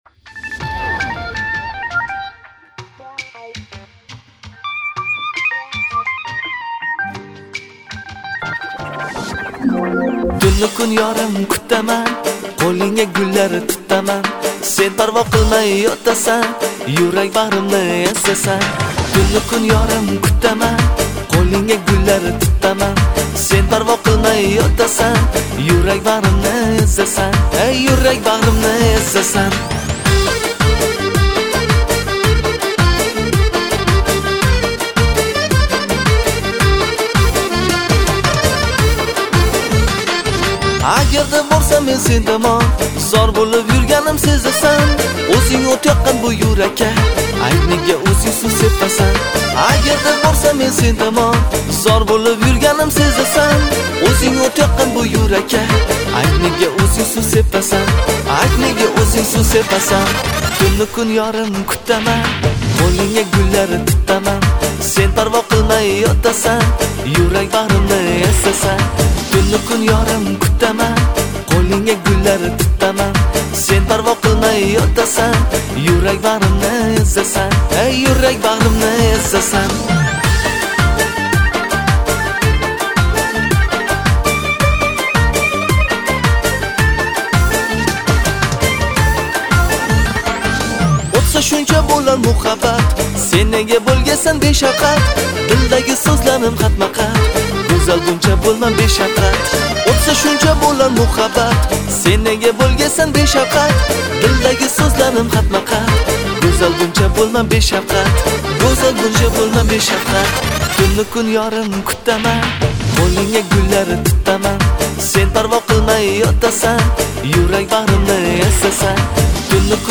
увас очень красивый голос !!!